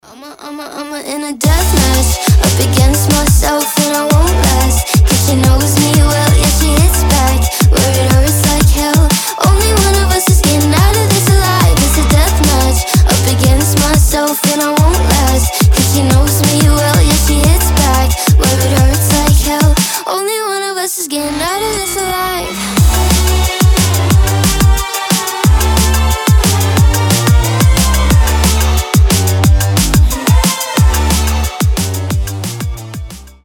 • Качество: 320, Stereo
Pop Rock
alternative
поп-панк